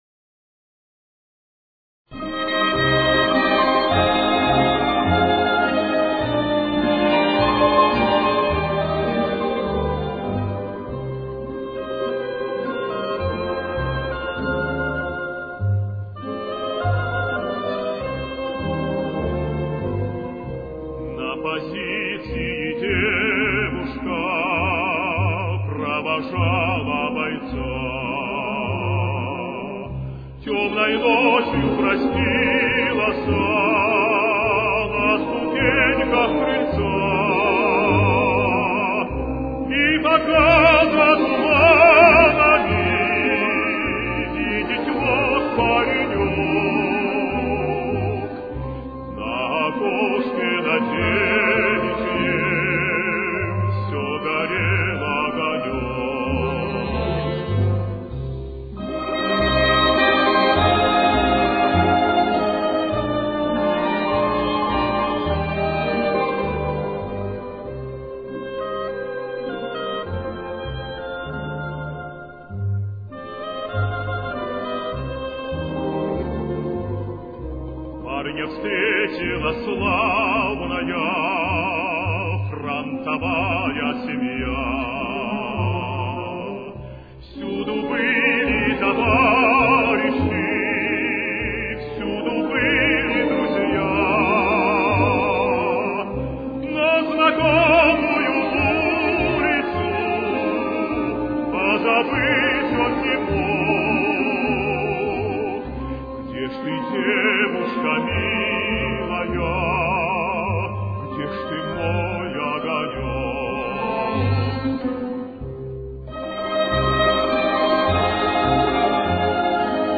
Темп: 104.